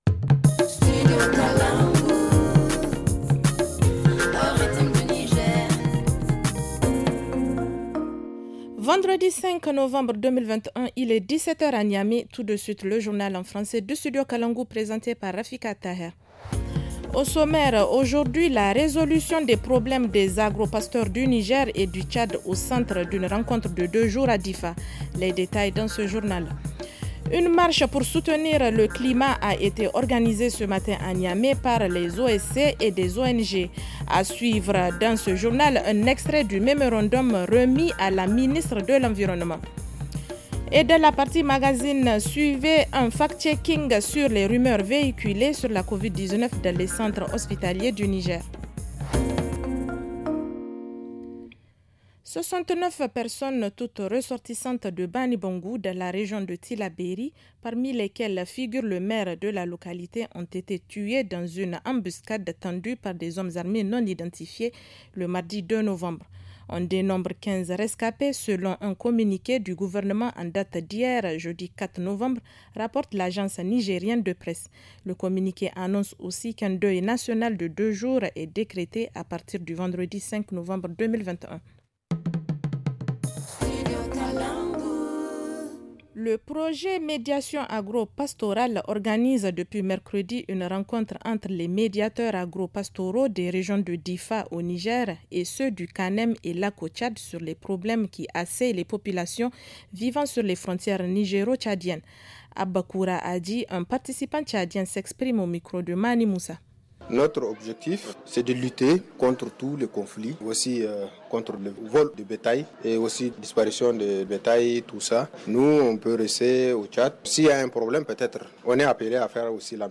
Le journal du 05 novembre 2021 - Studio Kalangou - Au rythme du Niger